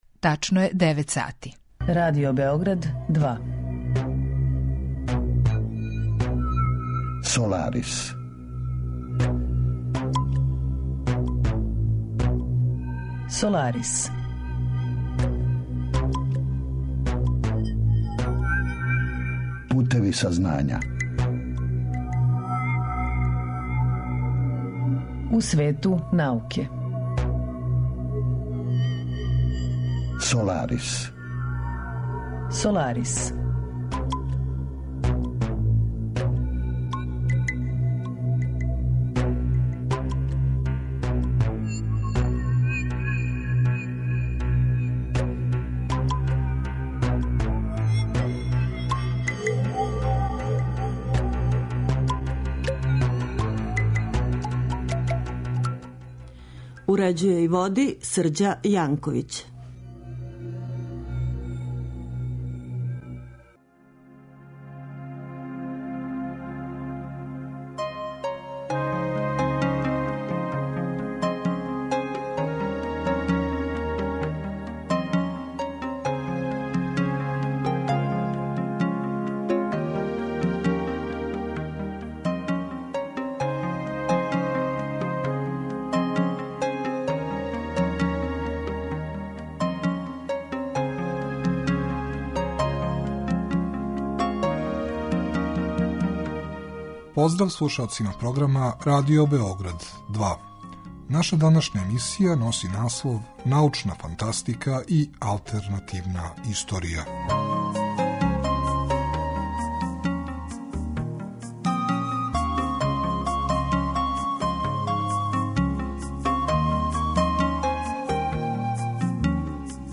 Тема емисије: 'Научна фантастика и алтернативна историја', а саговорник